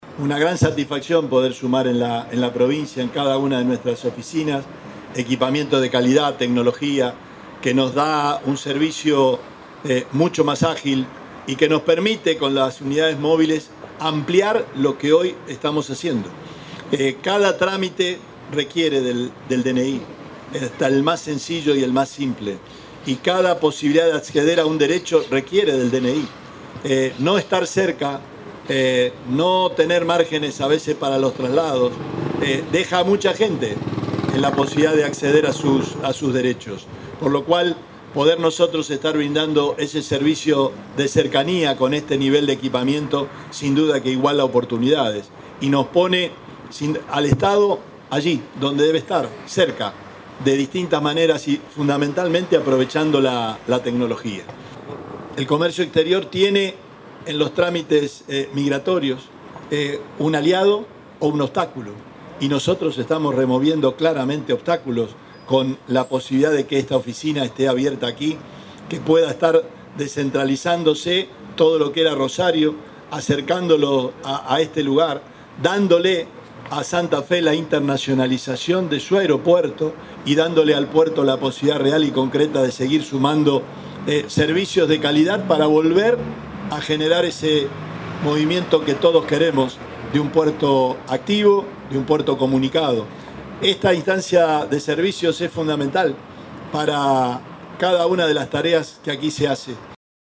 Declaraciones Perotti nueva oficina de Migraciones